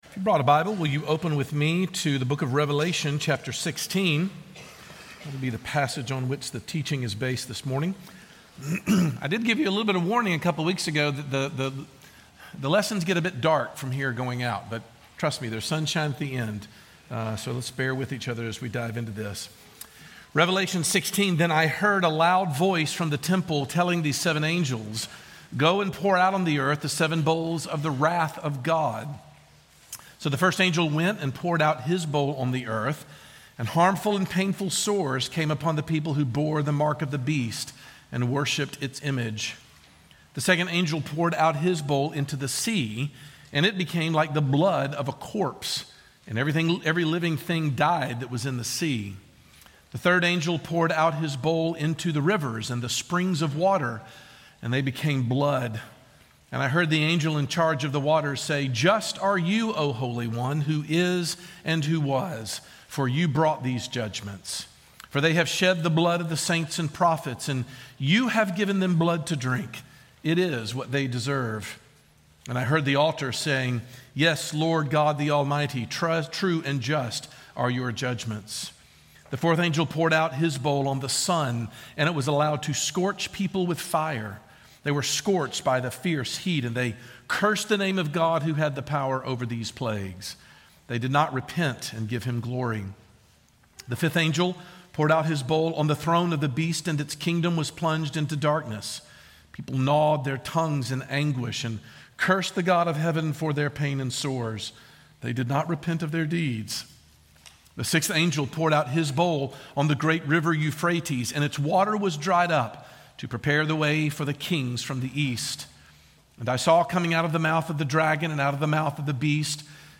How does a Christian view the ultimate end of evil and how does it inform how we live today? Sermon